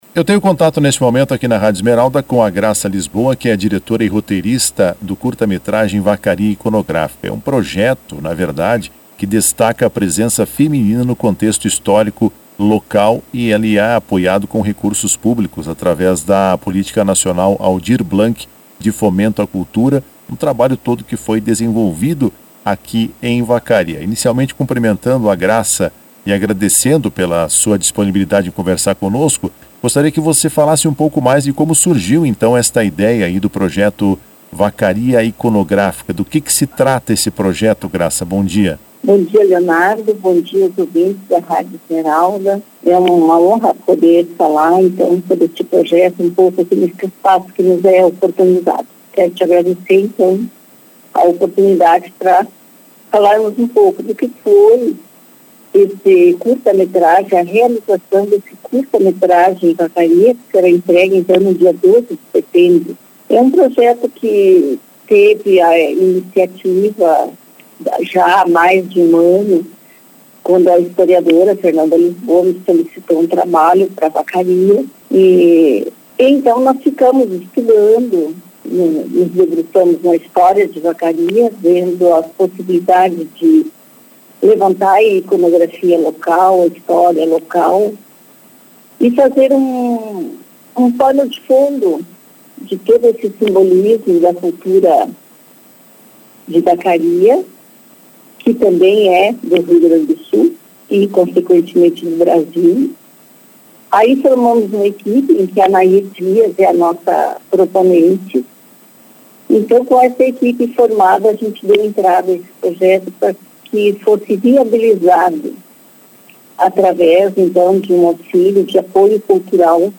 Em entrevista à Rádio Esmeralda